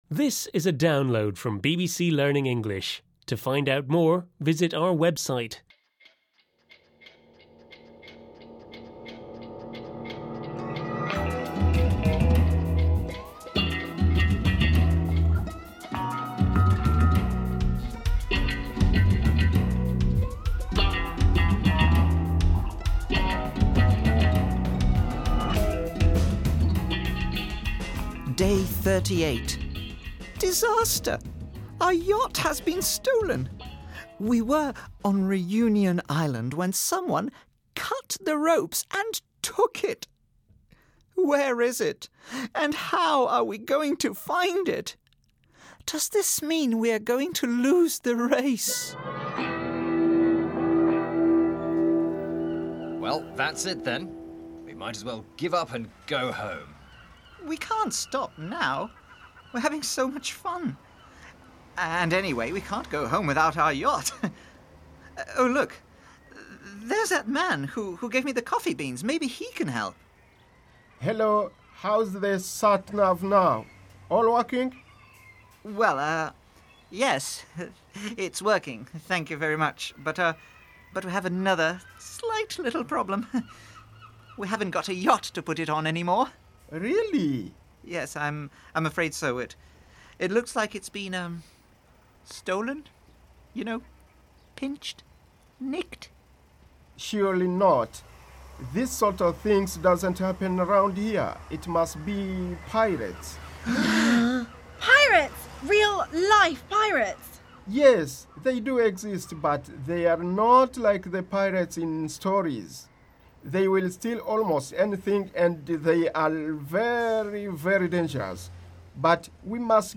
unit-6-5-1-u6_eltdrama_therace_download.mp3